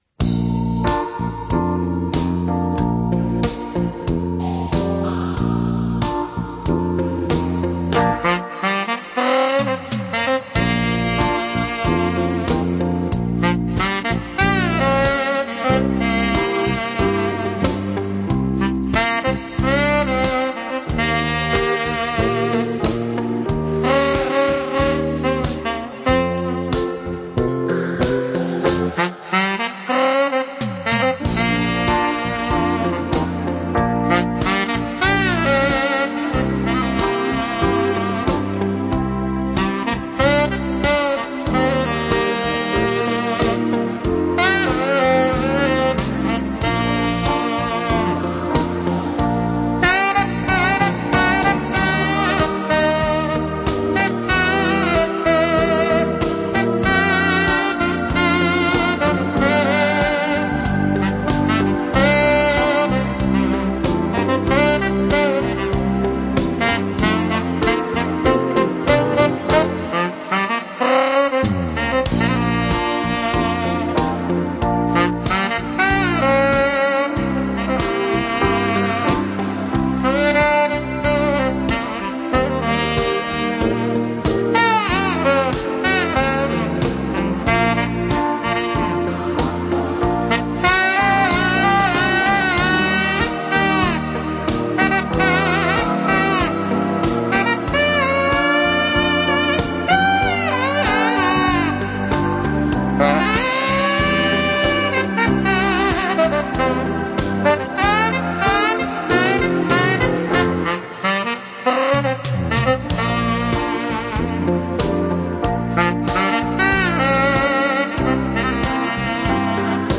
萨克斯
Жанр: Easy Listening, Sax